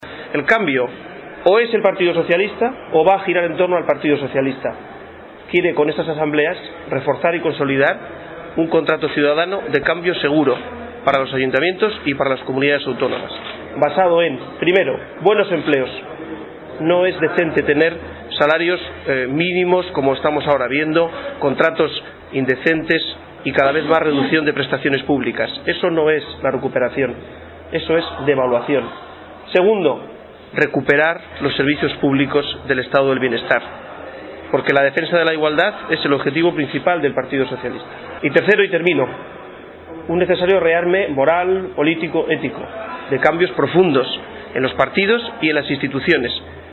En la consecución de ese objetivo y de conectar con los ciudadanos, Luena participó en Ciudad Real en una asamblea abierta junto al secretario general del PSOE en la provincia, José Manuel Caballero, y la candidata a la Alcaldía de la capital, Pilar Zamora.